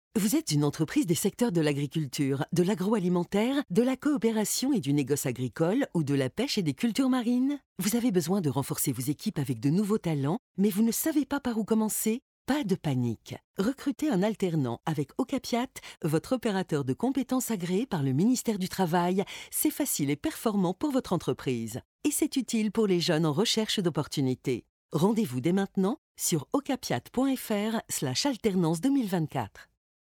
SPOT RADIO ALTERNANCE
SPOT-RADIO-OCAPIAT-alternance.mp3